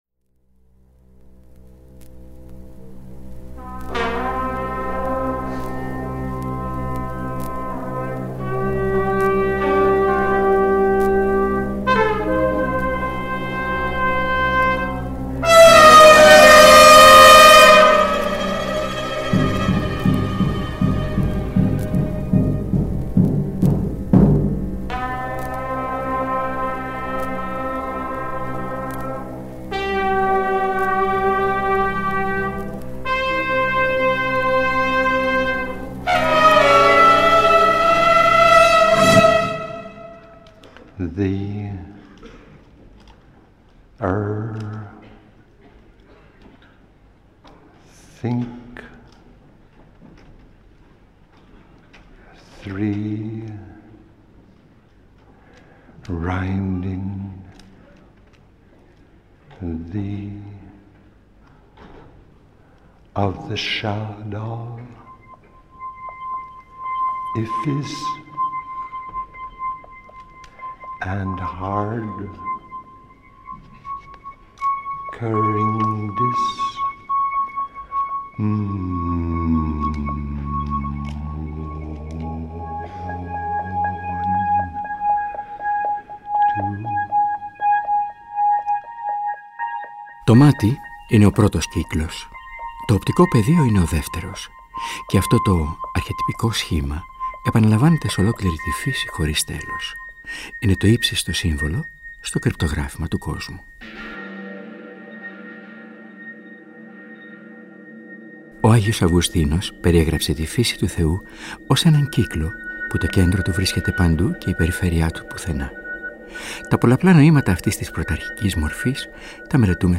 Πρόκειται για μια θεματική εκπομπή που κάθε φορά καταπιάνεται με ένα θέμα κοινωνικού, φιλοσοφικού περιεχομένου, δομημένο ραδιοφωνικά κυρίως μέσα από την πρόζα (με κείμενα όπως π.χ. των: Μπαρτ, Κίρκεργκωρ, Βιρίλιο, Φουκώ, Καροτενούτο, Ντεμπόρ, Αξελού, Παπαγιώργη, Γραμματικάκη κ.α.), τη μουσική και τους ήχους.
Ποικίλα κείμενα, διεθνής μουσική και σπάνιο ηχητικό υλικό, συνθέτουν ένα ραδιοφωνικό τοπίο όπου ο ακροατής “πληροφορείται” ενώ παράλληλα “διασκεδάζει”, καθώς σκηνοθετικά μεταφέρεται στο φανταστικό χώρο που η εκάστοτε θεματική τον οδηγεί.
Κάθε εκπομπή αποτελεί ένα θεματικό ντοκουμέντο, συχνά με τη συμμετοχή καλεσμένων ηθοποιών και άλλων καλλίφωνων συνεργατών.